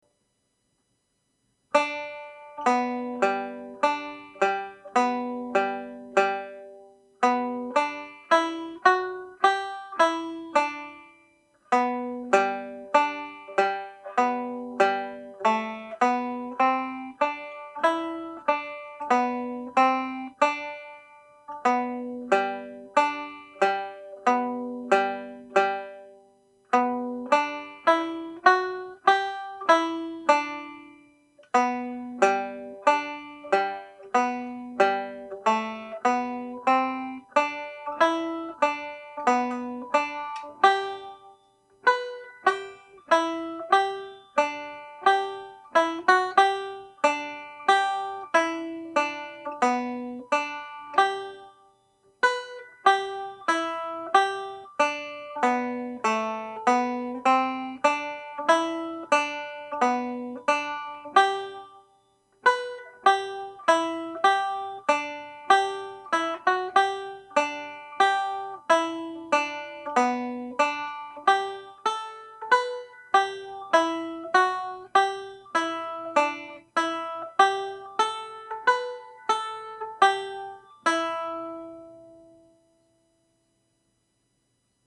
Reel (G Major)